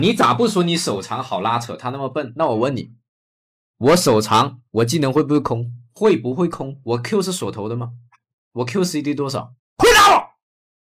Voix IA de doublage Sun Wukong
Synthèse vocale de personnage
Haute énergie
Développé à l'aide d'une technologie avancée de transfert de style, ce modèle vocal délivre le ton iconique, aigu et énergique associé à la légende du Voyage vers l'Ouest.